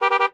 highway / oldcar / honk2.ogg
honk2.ogg